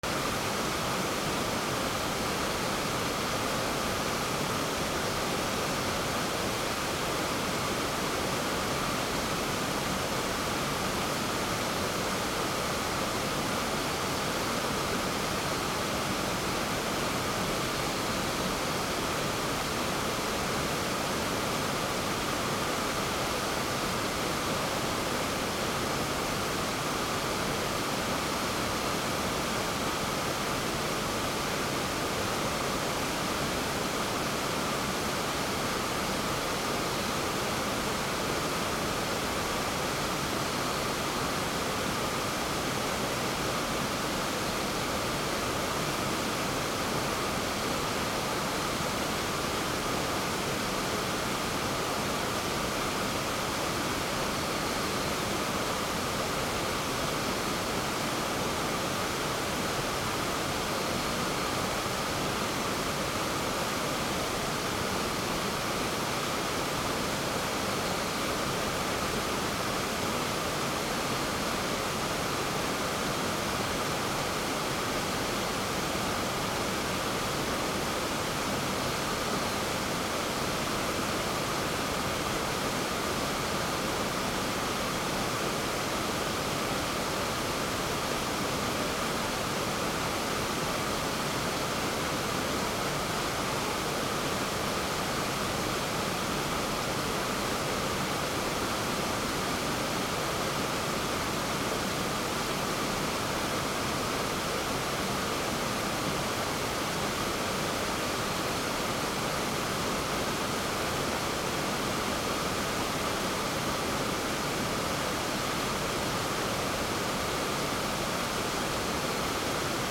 / B｜環境音(自然) / B-15 ｜水の流れ